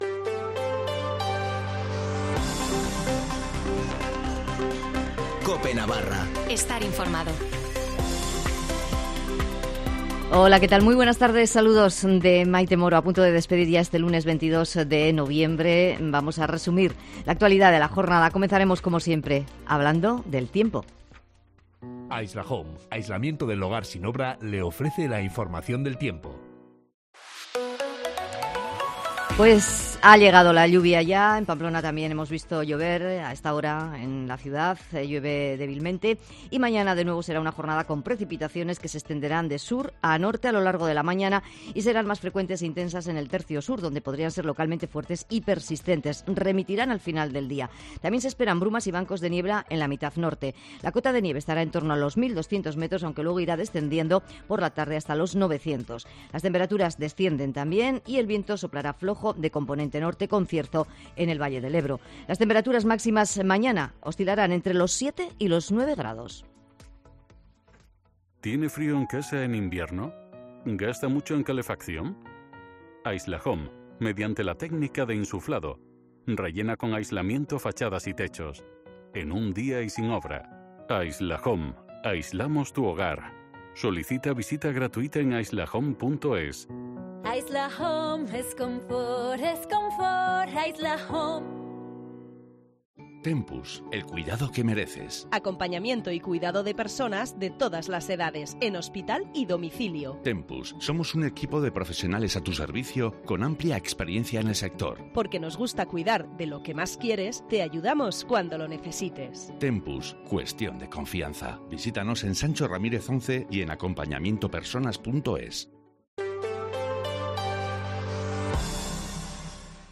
Informativo de las 19:50 en Cope Navarra (22/11/2021)